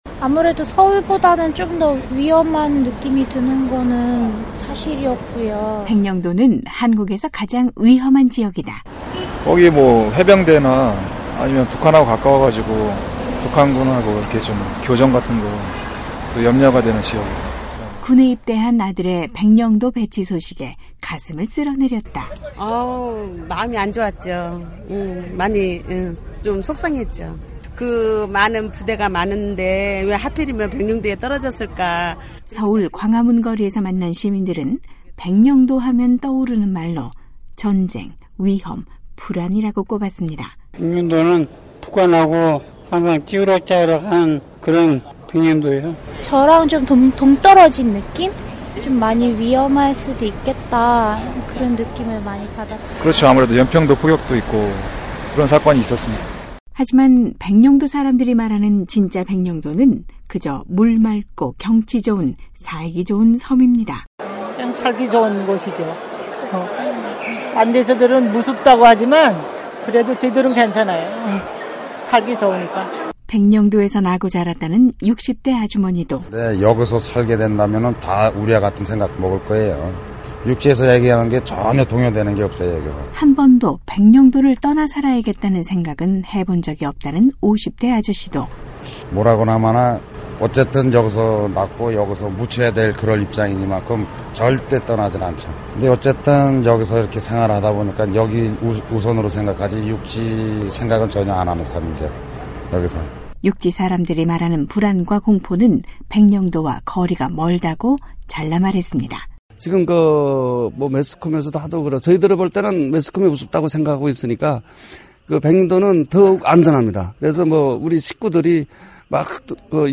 오늘은 지난 시간에 이어 서해바다 최북단 섬, 백령도 소식- 백령도 주민들의 목소리를 들어보겠습니다.